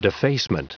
Prononciation du mot defacement en anglais (fichier audio)
Prononciation du mot : defacement